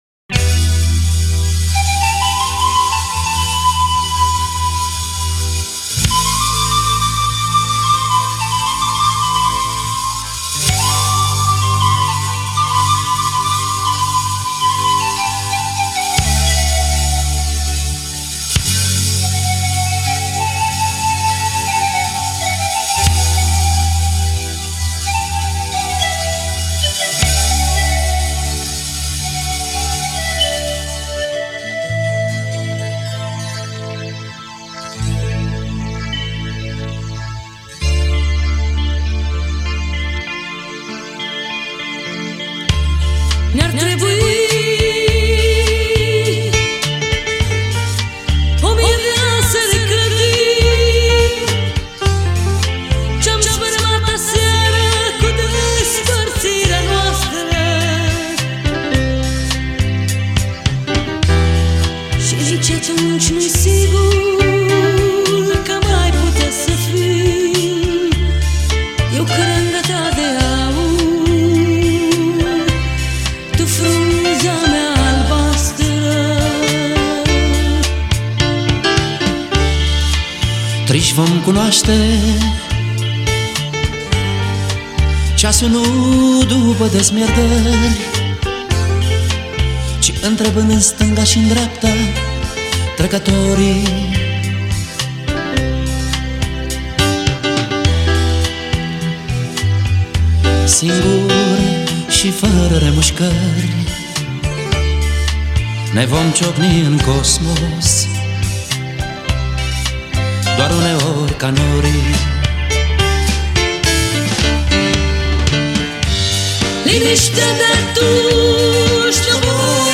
interpretată în duet